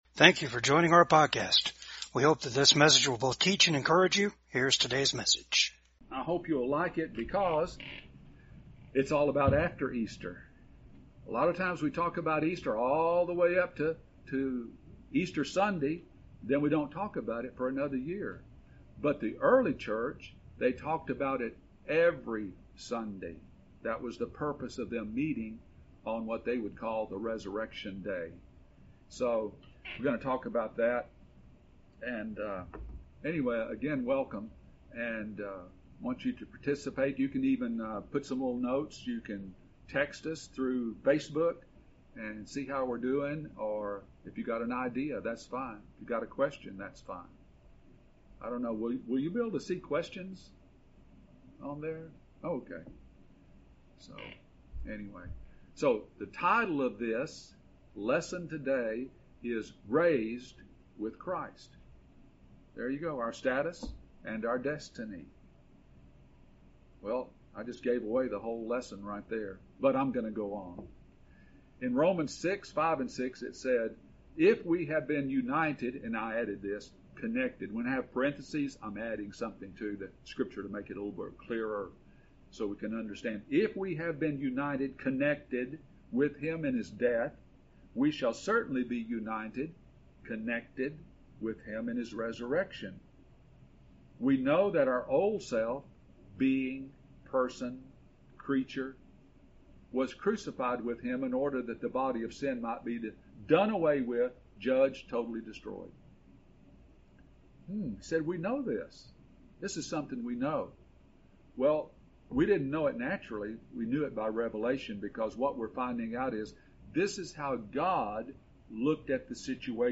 Service Type: VCAG WEDNESDAY SERVICE